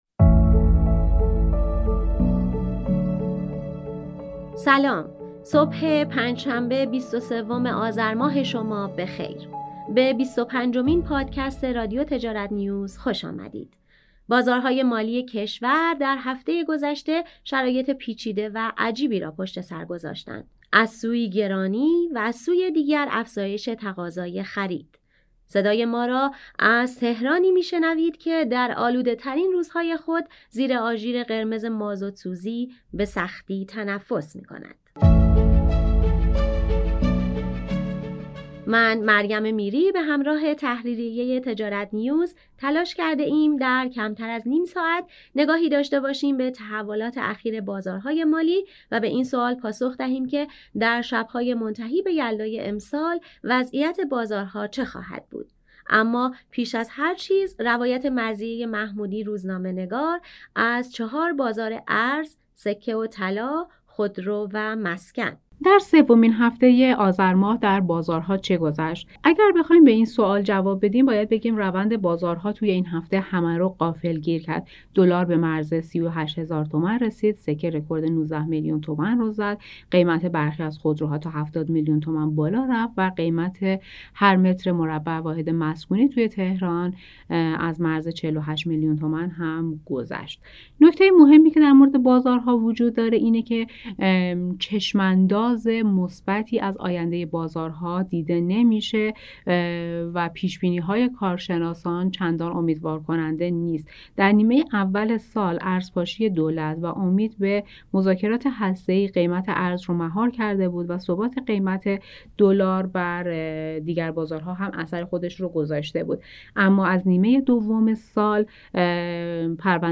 گزارش میدانی از قیمت میوه شب یلدای امسال